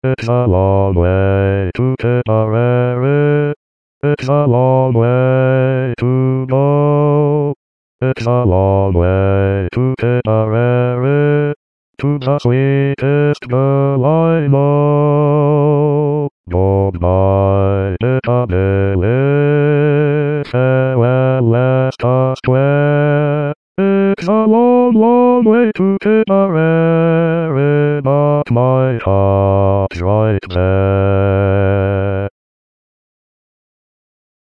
Bass MP3